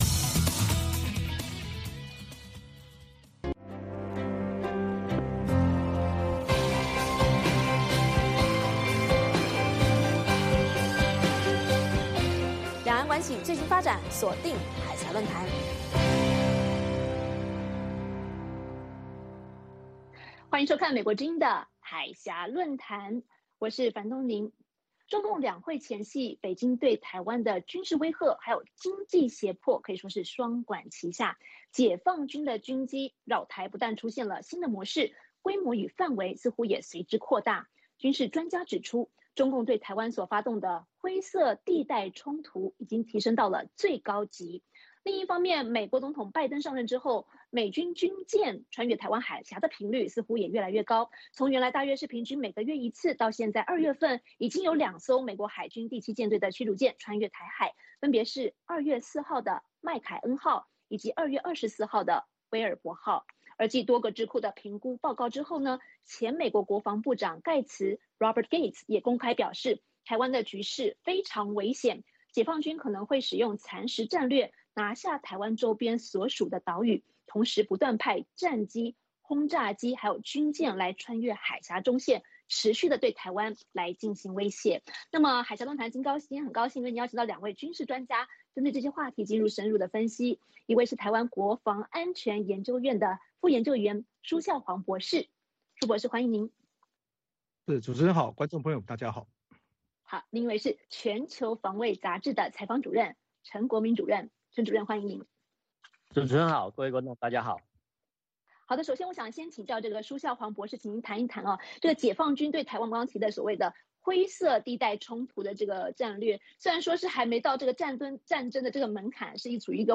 美国之音中文广播于北京时间每周日晚上9点播出《海峡论谈》节目(电视、广播同步播出)。《海峡论谈》节目邀请华盛顿和台北专家学者现场讨论政治、经济等各种两岸最新热门话题。